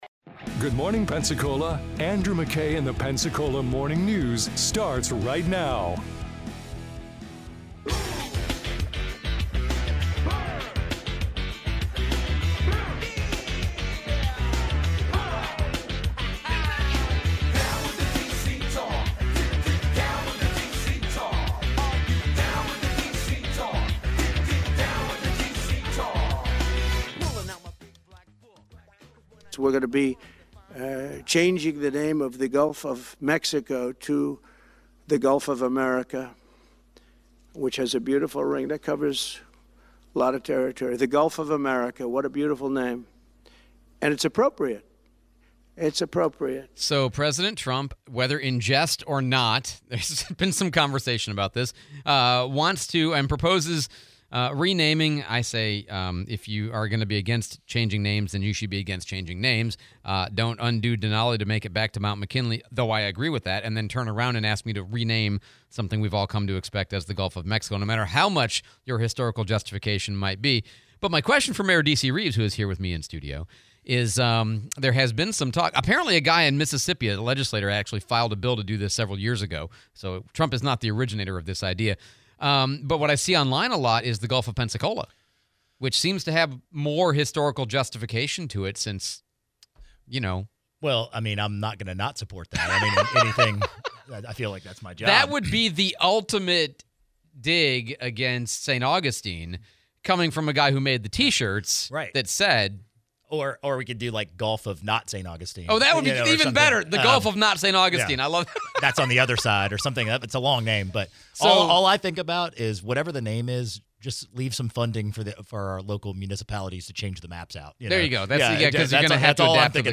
Mayor DC Reaves Radio Town Hall part 2, Topic: Which burner on the stove is your go to burner??